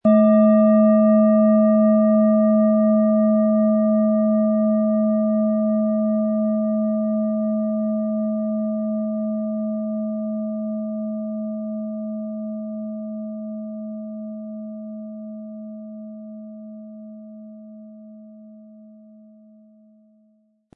Tibetische Bauch-Becken- und Universal-Klangschale, Ø 22,2 cm, 1000-1100 Gramm, mit Klöppel
Im Sound-Player - Jetzt reinhören können Sie den Original-Ton genau dieser Schale anhören.
HerstellungIn Handarbeit getrieben
MaterialBronze